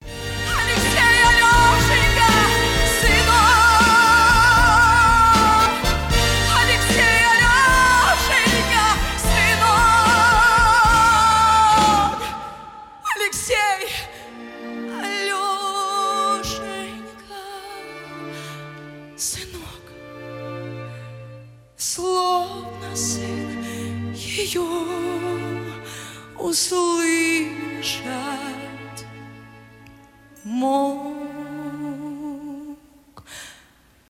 cover , live